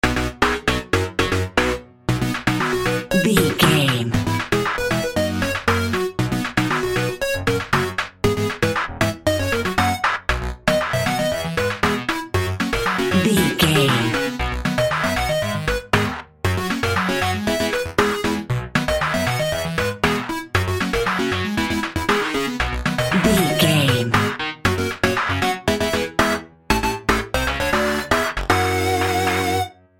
Ionian/Major
bouncy
cheerful/happy
funky
groovy
lively
playful
uplifting
synthesiser
drum machine